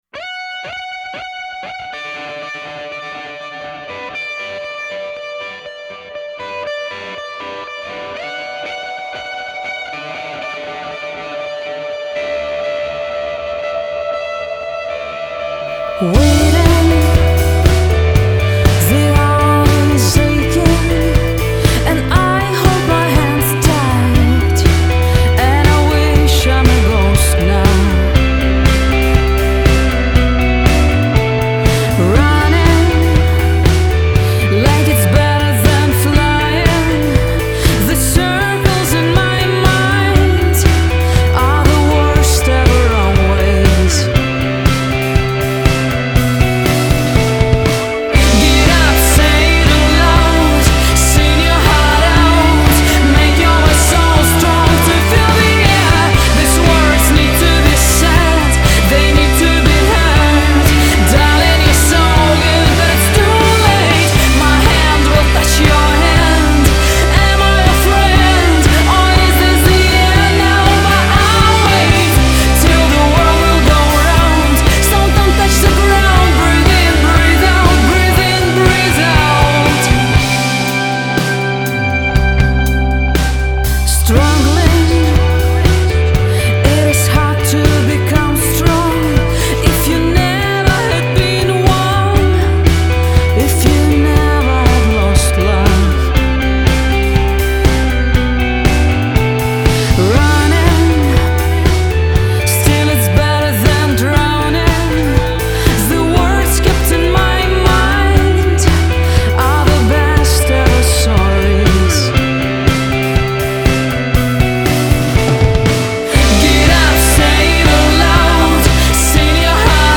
Indie-Rock Band
Gesang
Schlagzeug
Genre: Indie / Rock